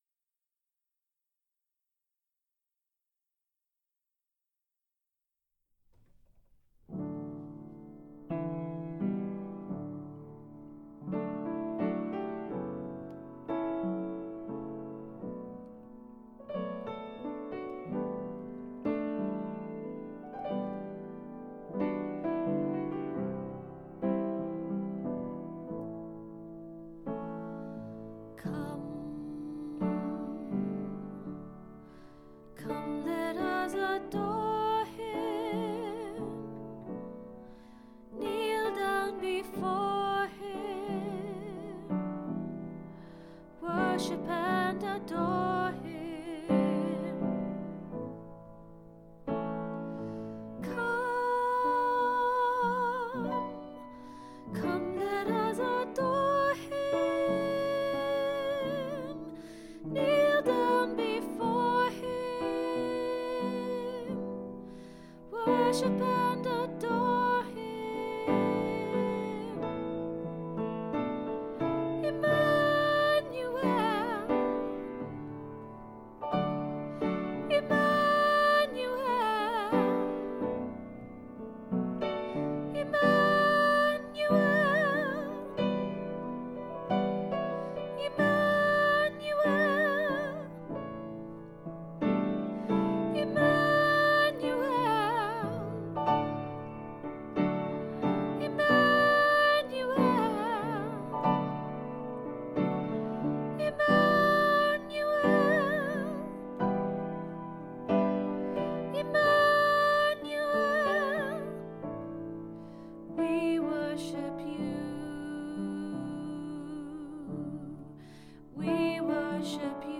Emmanuel Soprano & Bass - Three Valleys Gospel Choir
Emmanuel Soprano & Bass
Emmanuel-Soprano-Bass.mp3